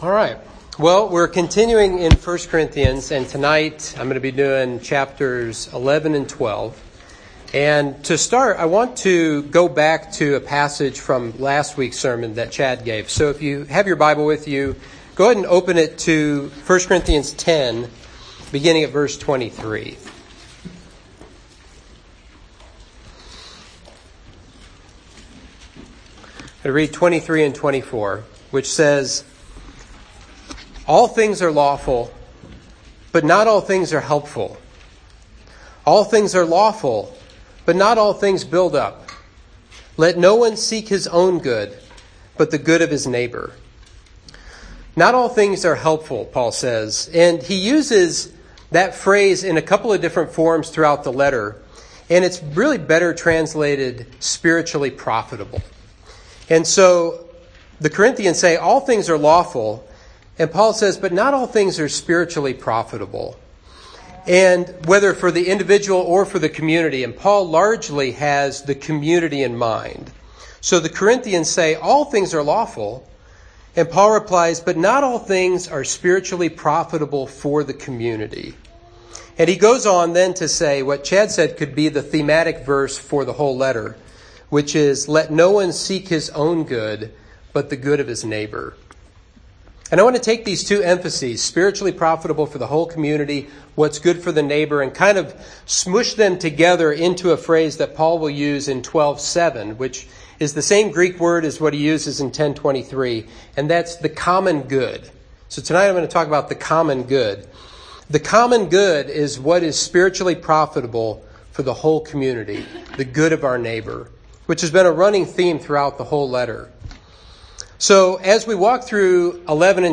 Sermon 5/14: 1 Corinthians 11-12: The Common Good